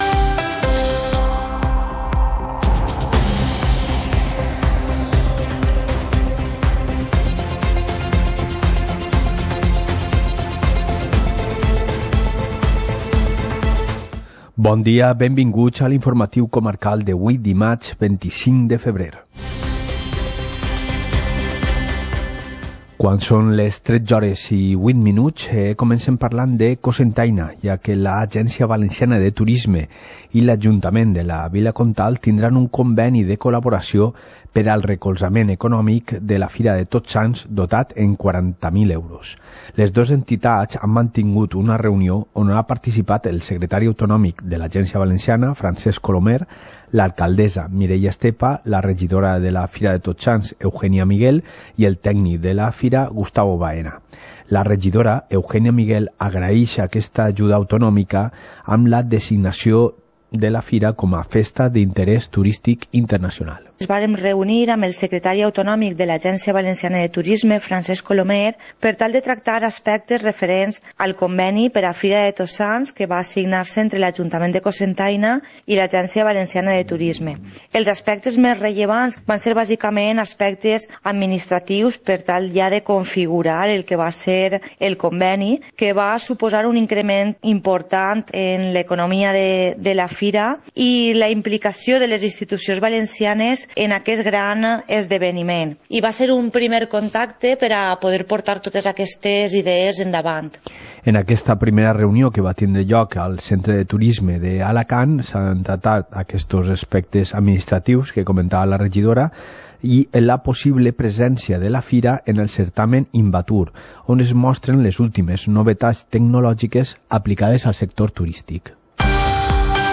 Informativo comarcal - martes, 25 de febrero de 2020